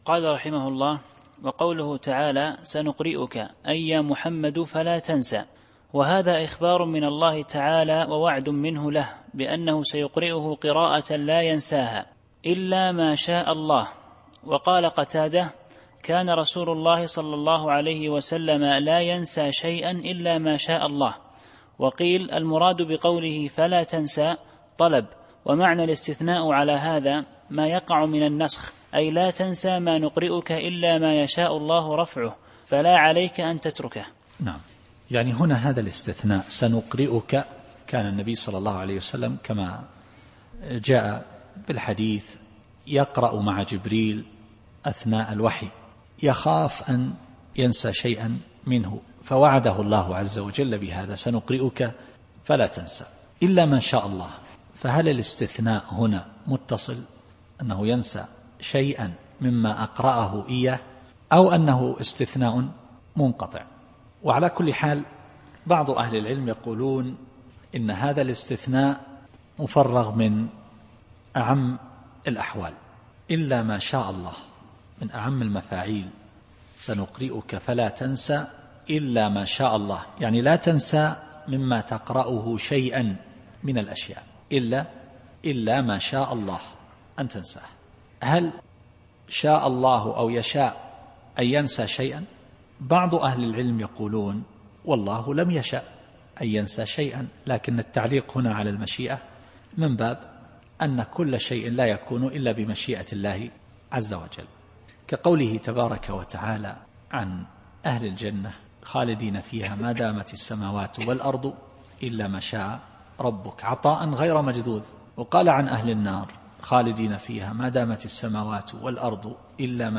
التفسير الصوتي [الأعلى / 6]